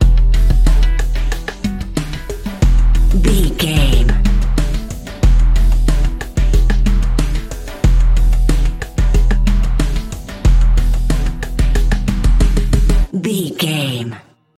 Aeolian/Minor
synthesiser
drum machine
hip hop
Funk
neo soul
acid jazz
energetic
cheerful
bouncy
funky